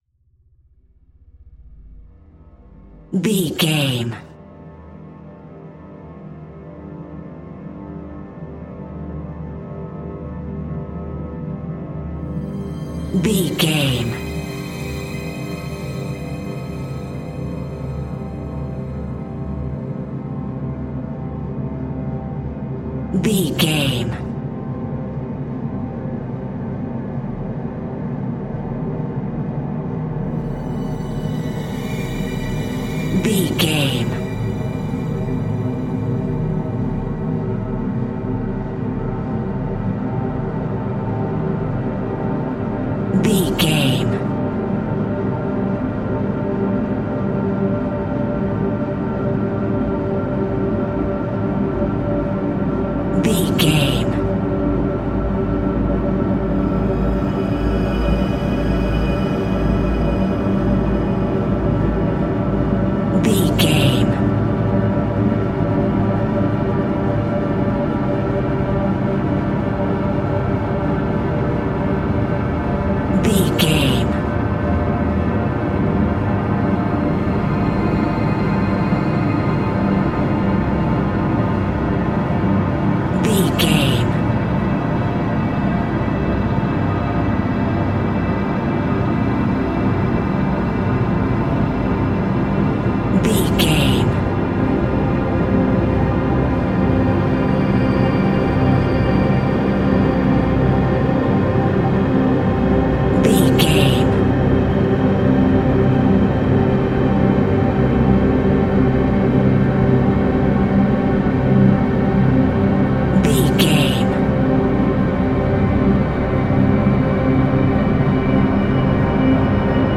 Eerie Horror Build Up.
In-crescendo
Atonal
Slow
tension
ominous
haunting
strings
synth
ambience
pads